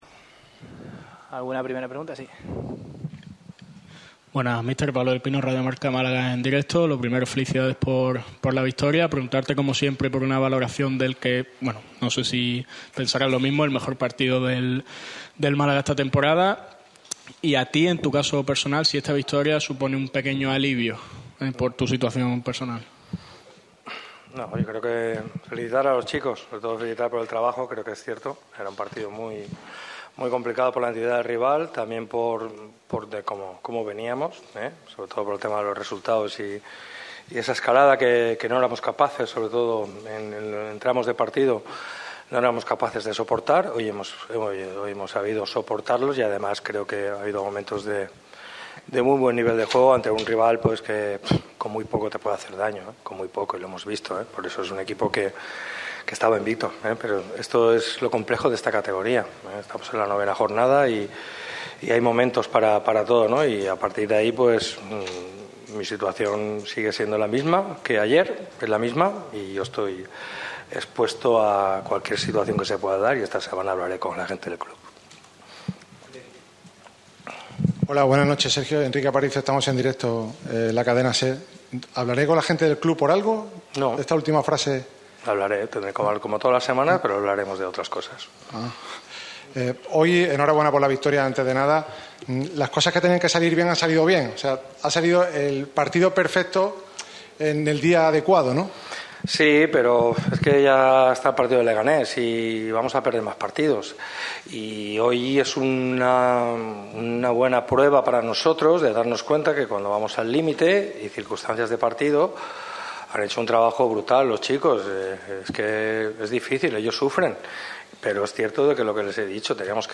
Pellicer afirma en rueda de prensa que hablará con la gente del club, como cada semana, pero de "otras cosas"